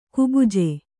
♪ kubuje